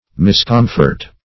Miscomfort \Mis*com"fort\, n.